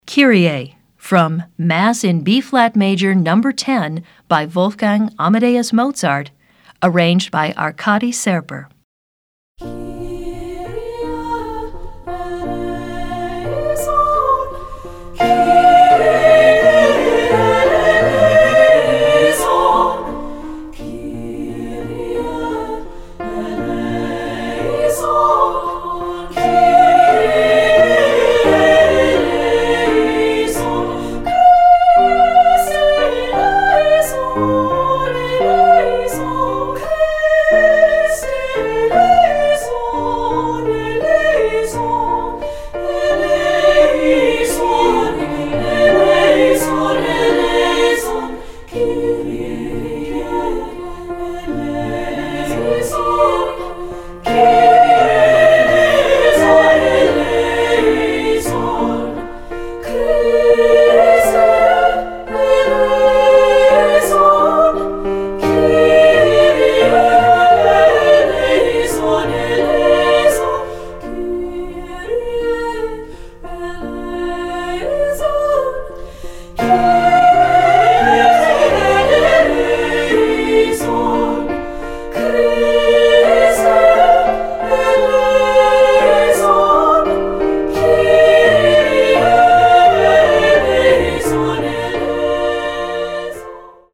Voicing: 3-Part Treble